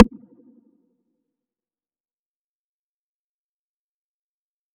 Closed Hats
Blip_reverb.wav